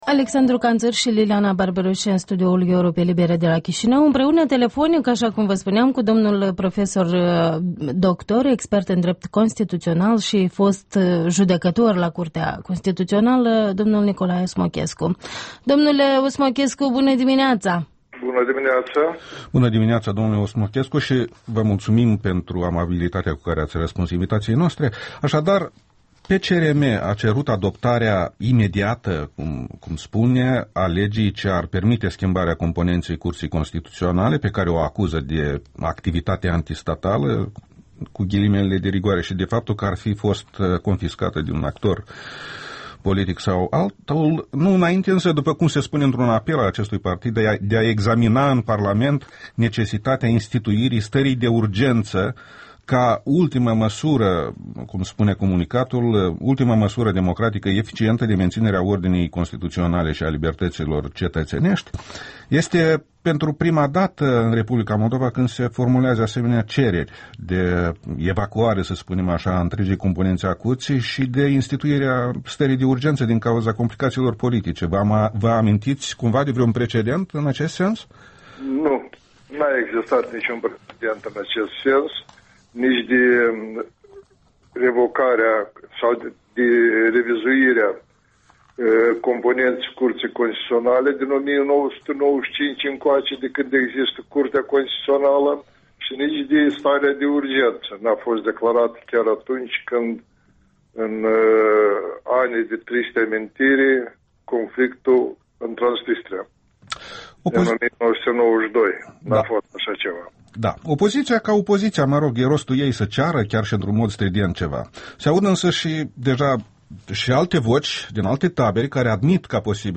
Interviul dimineții: cu judecătorul Nicolae Osmochescu despre criza politică actuală